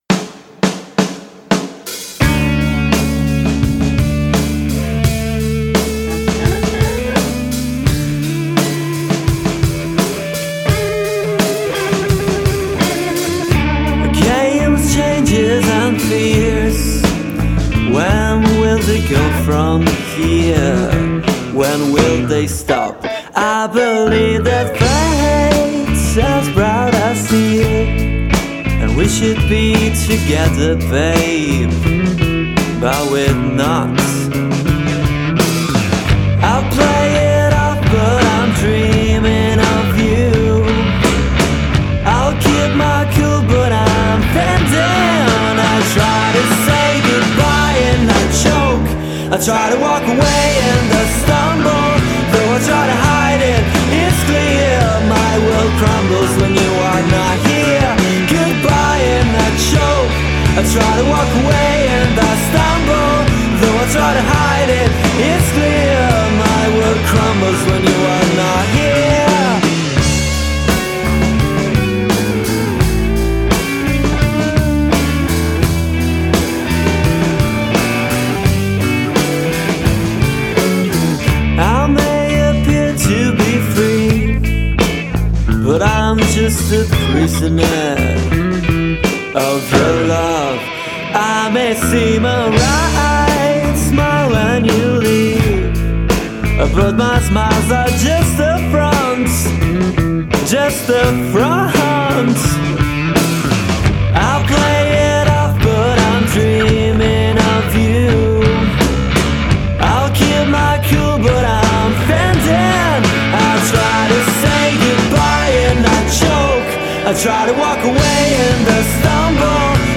College rock al suo meglio, ruvido e scanzonato.